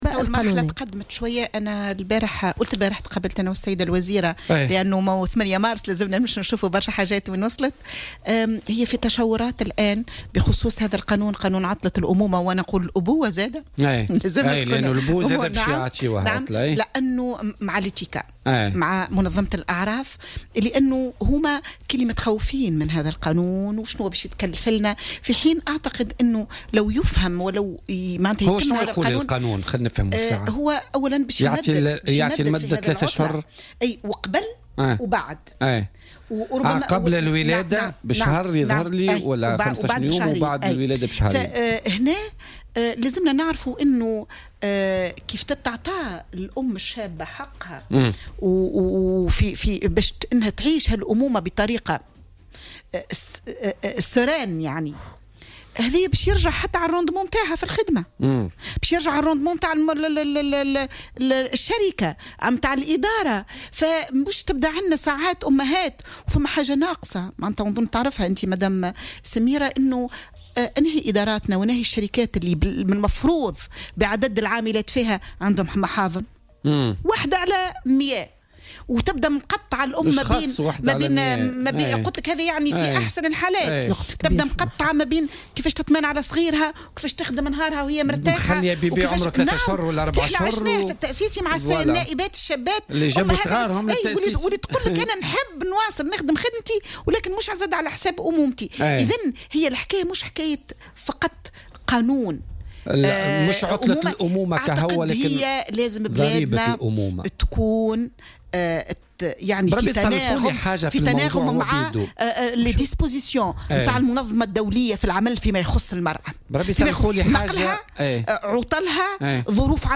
وأضافت العبيدي خلال استضافتها اليوم الخميس 8 مارس 2018، في برنامج "بوليتيكا"، أن منظمة الأعراف أعربت عن تخوفها من هذا القانون.